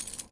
R - Foley 16.wav